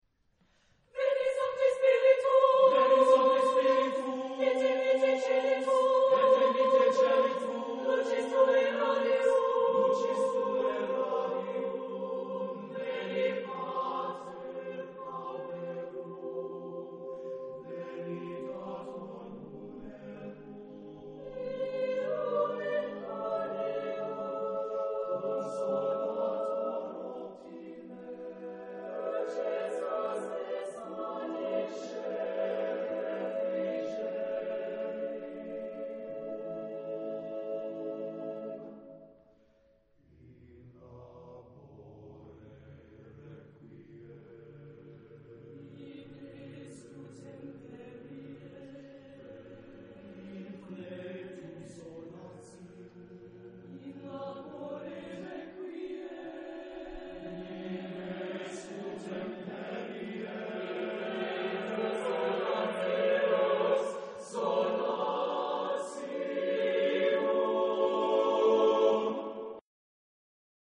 Type de matériel : Partition choeur seul
Genre-Style-Forme : contemporain ; Sacré
Type de choeur : SSAATTBB  (8 voix mixtes )